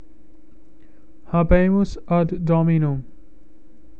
Hah-bay-moos    add      dom- ee-noom.